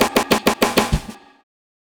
Swinging 60s Drumz Fill.wav